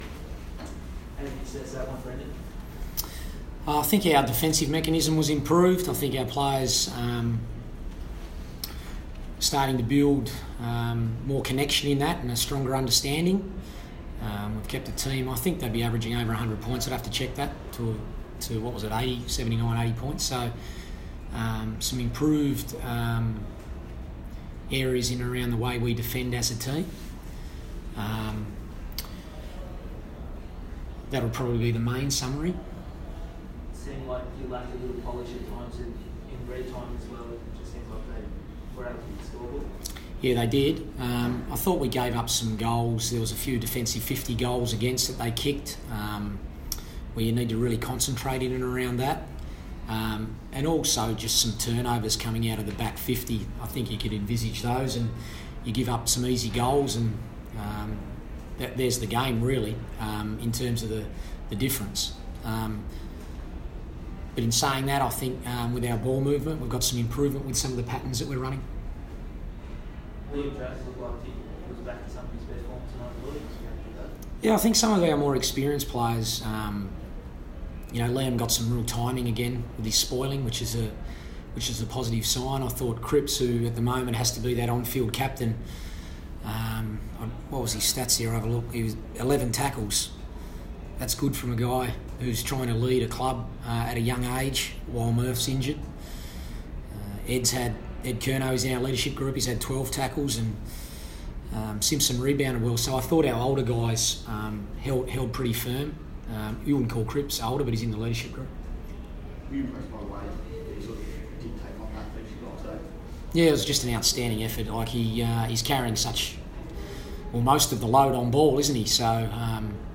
Carlton coach Brendon Bolton speaks to the media after the Blues' 10-point loss to West Coast at the MCG.